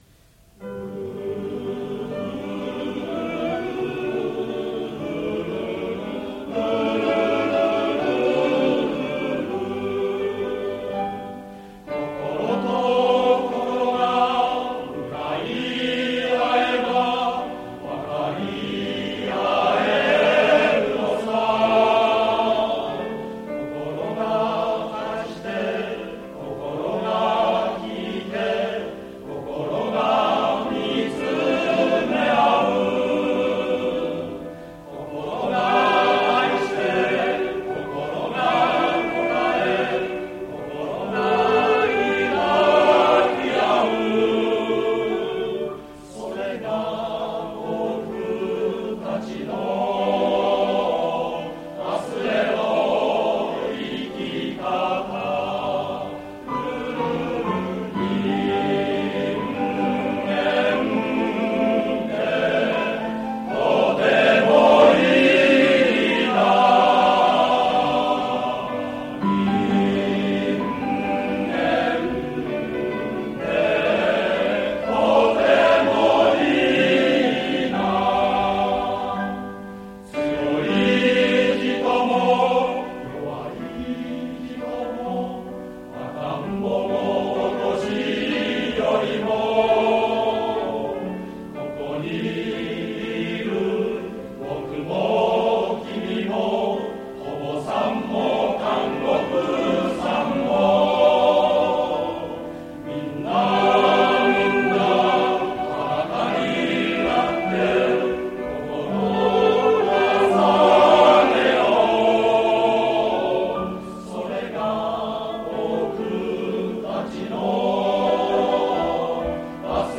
（1992年9月、ＴＭＣ第1回定演での初演奏）